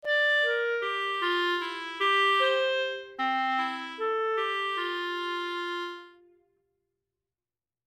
256 - L18P23 - lecture chantée - complet